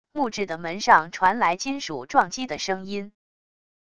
木质的门上传来金属撞击的声音wav音频